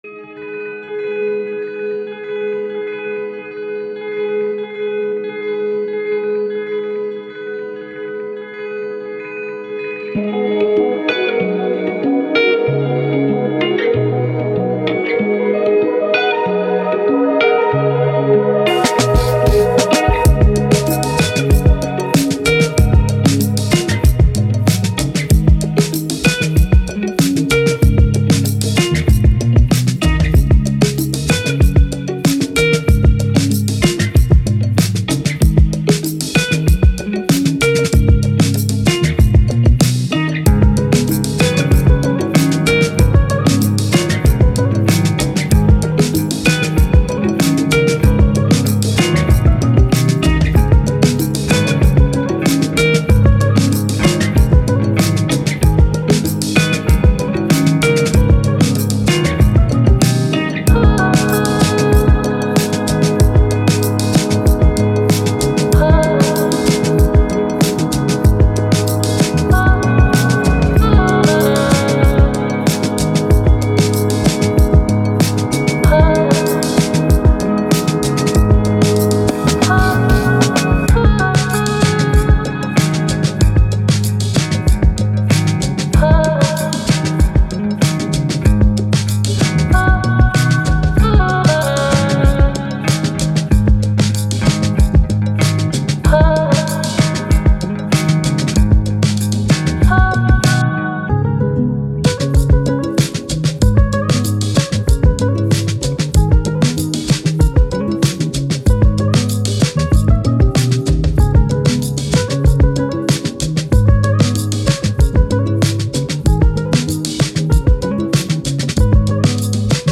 Ambient, Downtempo, Trip Hop, Thoughtful, Landscapes